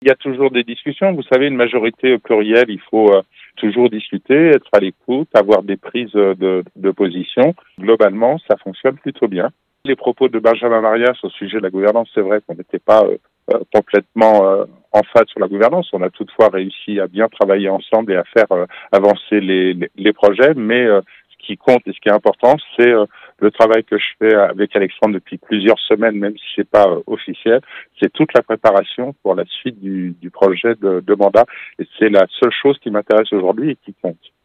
On écoute François Astorg: Conseil municipal à Cap Periaz à Seynod à partir de 18h.